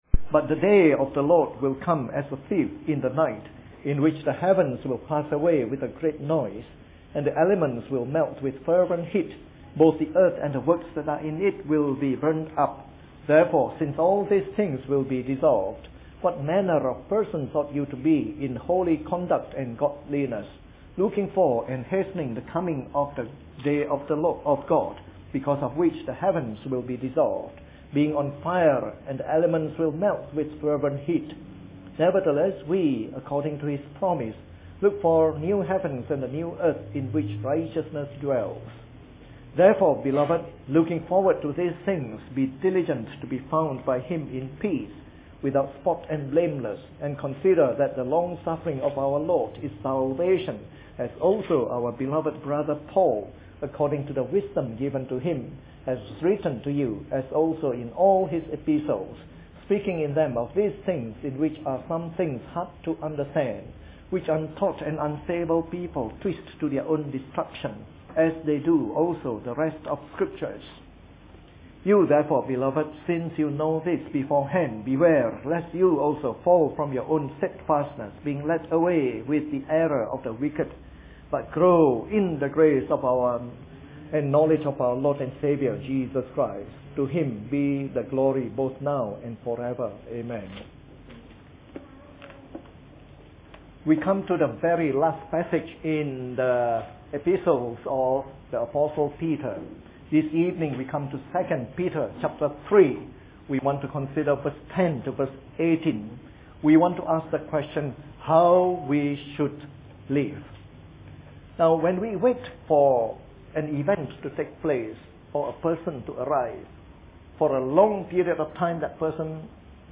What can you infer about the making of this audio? This is the conclusion of our series on “The Epistles of Peter” delivered in the Evening Service.